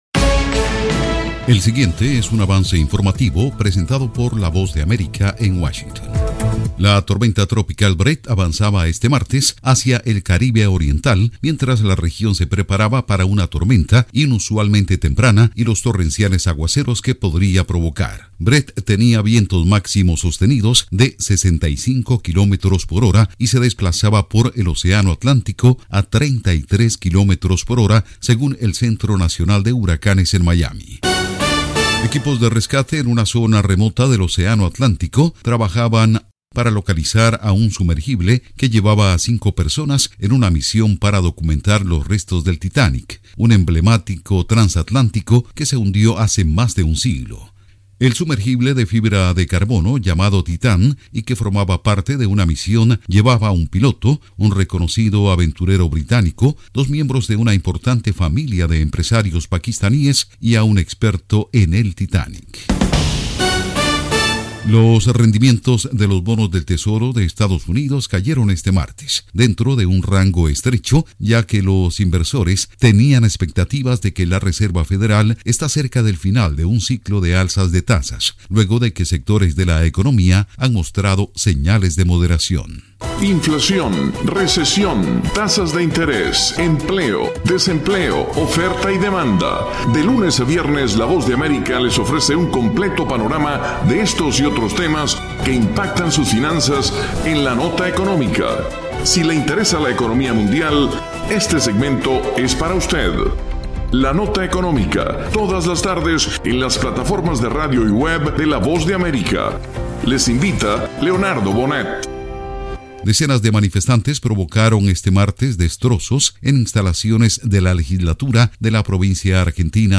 Avance Informativo 2:00 PM
El siguiente es un avance informativo presentado por la Voz de América en Washington.